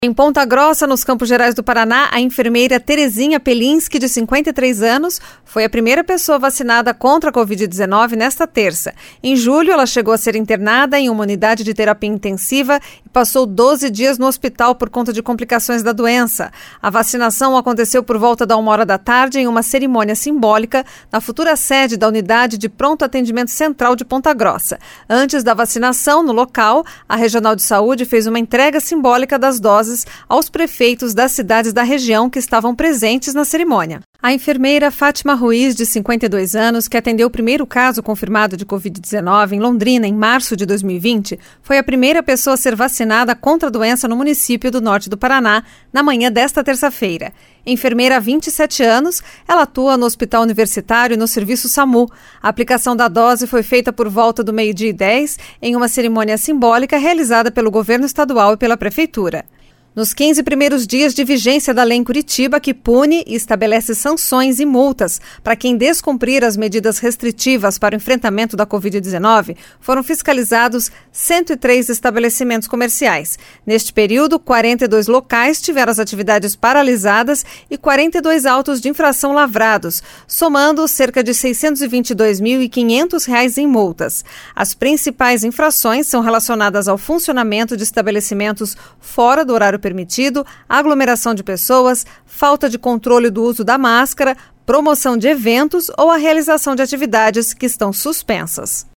Giro de Notícias Tarde SEM TRILHA